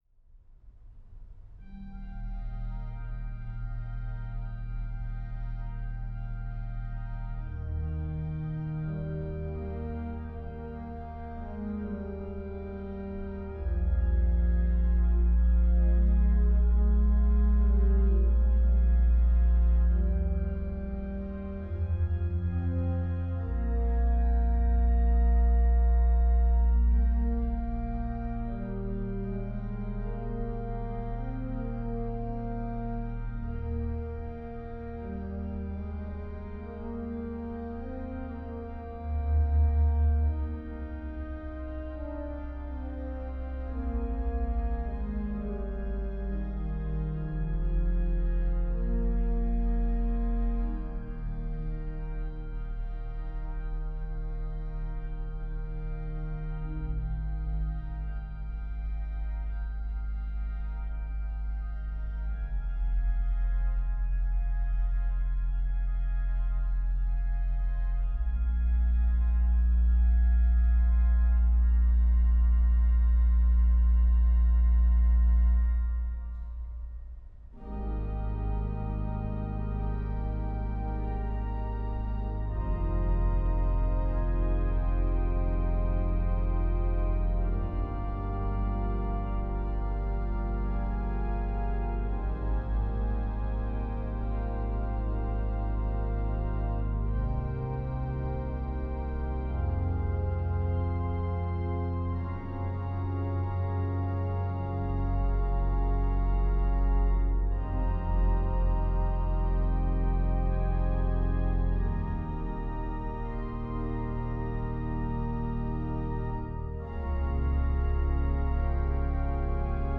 Voicing: Org 3-staff